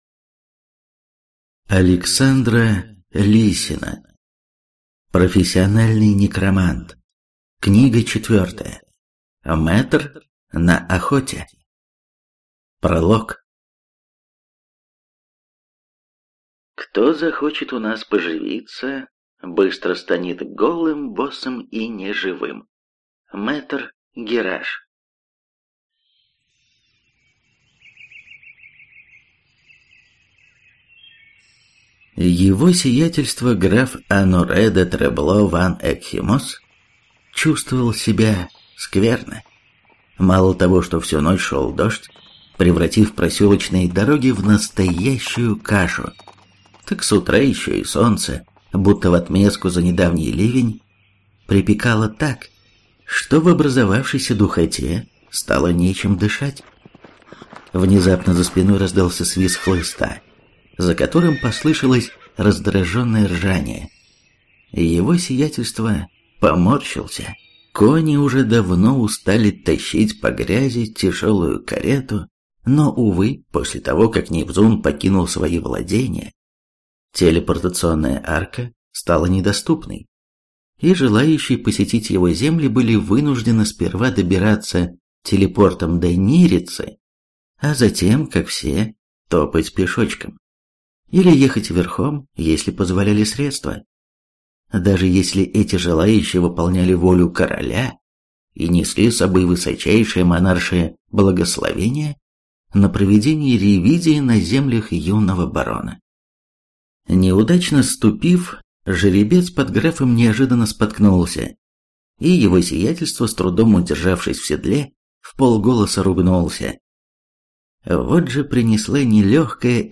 Прослушать фрагмент аудиокниги Профессиональный некромант.